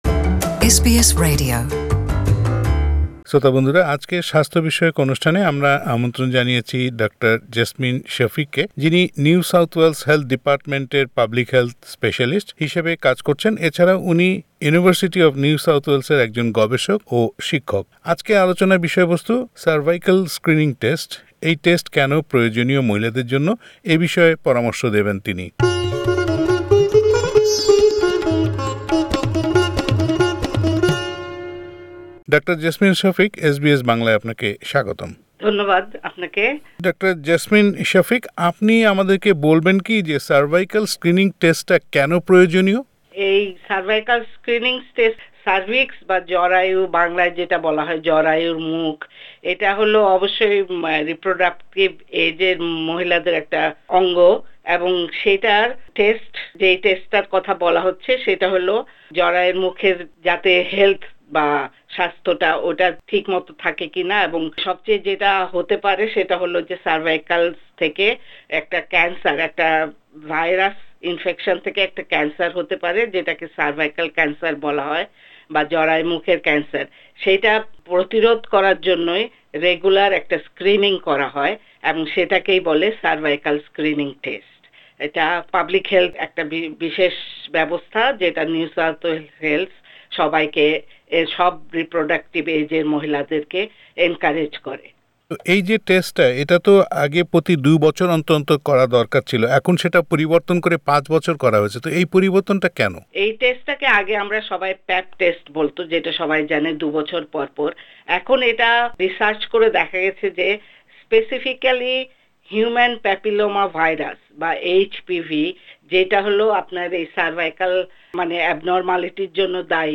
সারভিক্যাল ক্যান্স্যার নিয়ে এস বি এস বাংলার সাথে কথা বলেছেন